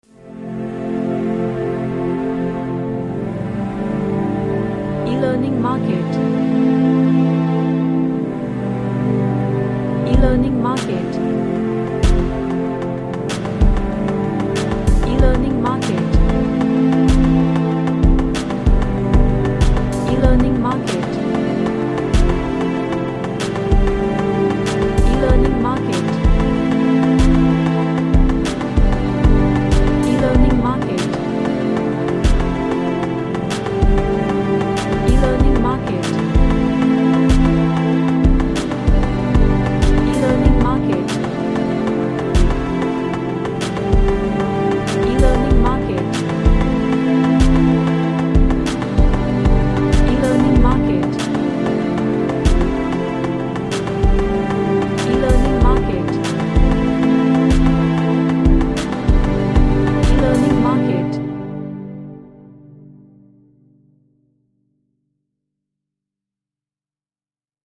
A Relaxing music with ambient pads.
Relaxation / Meditation